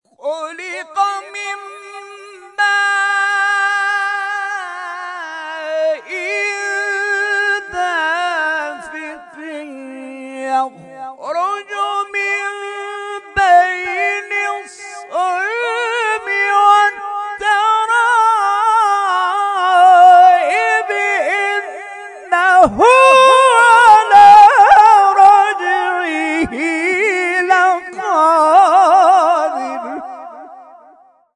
در این کرسی‌های تلاوت که آیات به صورت مکتوب نیز به منظور تدبر در قرآن در میان نمازگزاران توزیع می‌شود در پایان به کودکان و نوجوانان حاضر در مسجد، هدایایی اعطاء می‌شود.
در ادامه قطعات تلاوت این کرسی ها ارائه می‌شود.